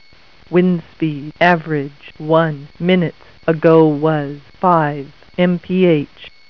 Weather Word connects to a standard telephone line, can run on batteries, and answers the phone with a pleasing female voice
Typical Telephone Answer: